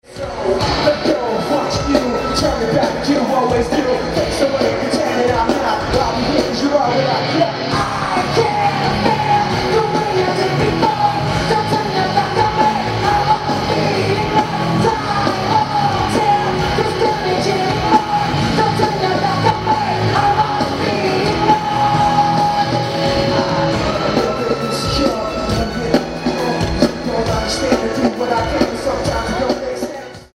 Venue:Manchester Evening News Arena
Source 1: Audio - AUD (MD: CSB > Bass roll off > MD)
• Location: Left of stage, about 40ft from stage speakers
• Comments: A little clipping every now and then.